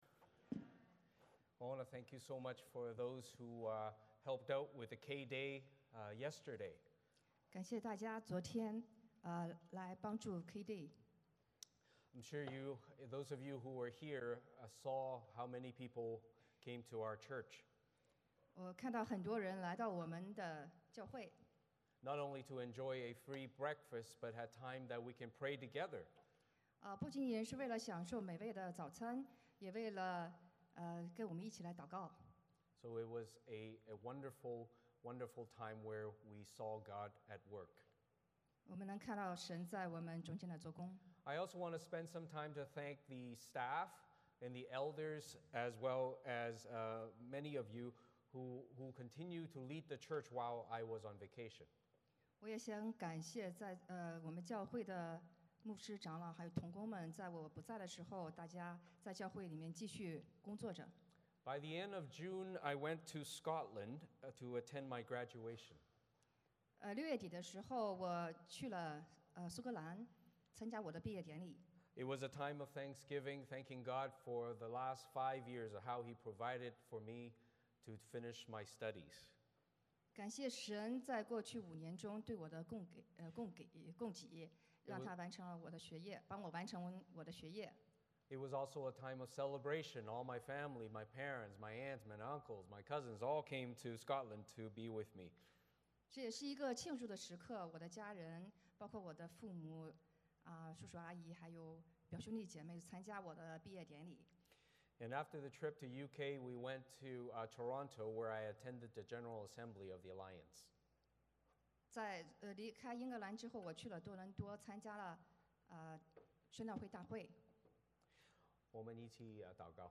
欢迎大家加入我们国语主日崇拜。
Passage: 使徒行传 1: 12-26 (中文标准译本) Service Type: 主日崇拜 欢迎大家加入我们国语主日崇拜。